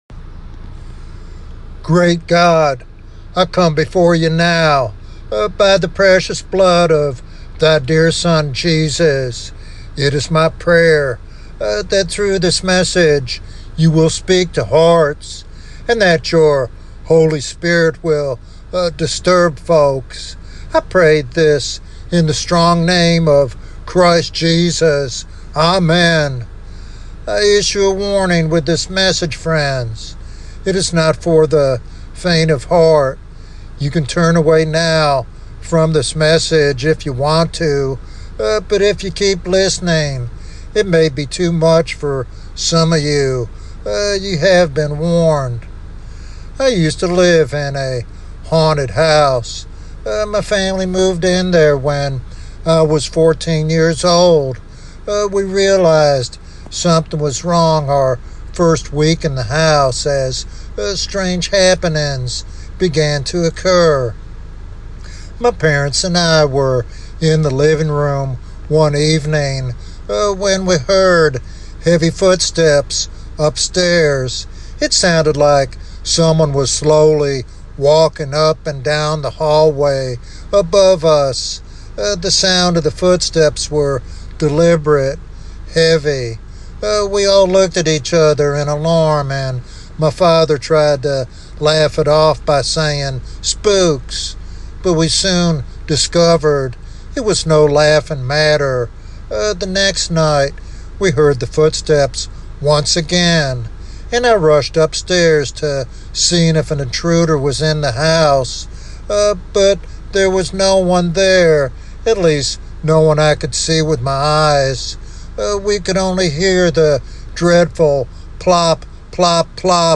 In this powerful evangelistic sermon